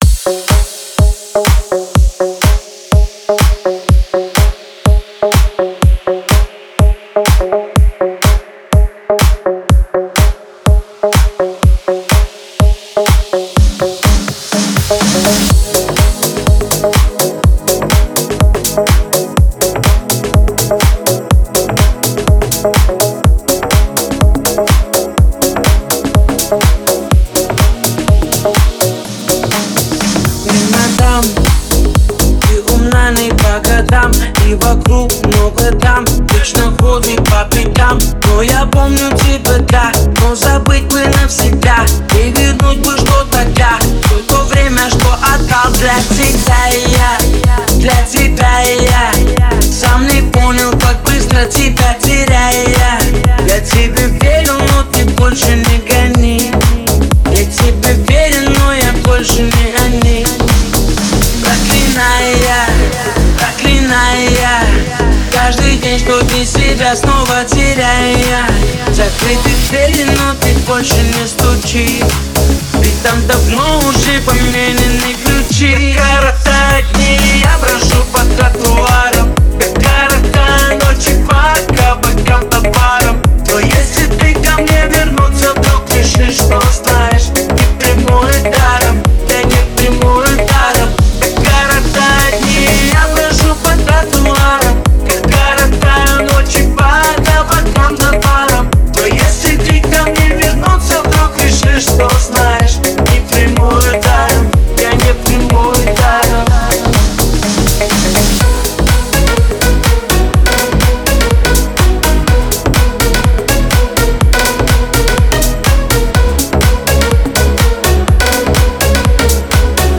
Файл в обменнике2 Myзыкa->Русская эстрада
Стиль: Dance / Pop